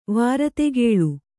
♪ vārategēḷu